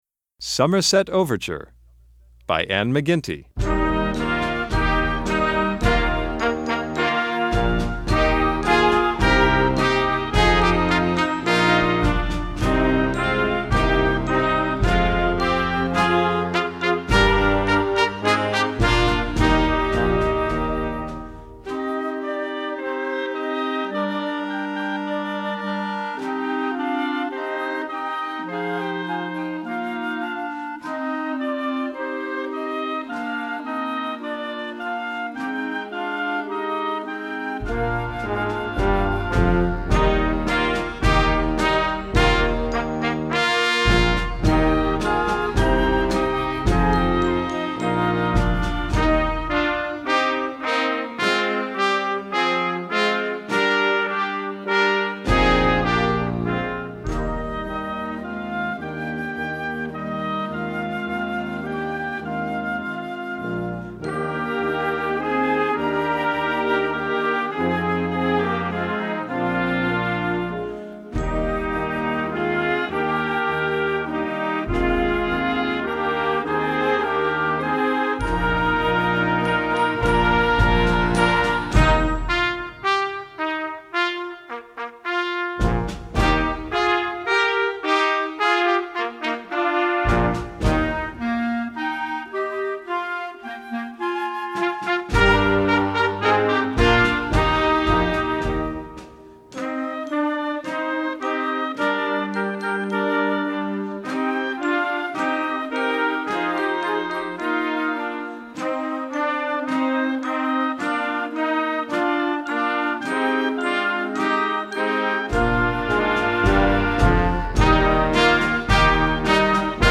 Band selections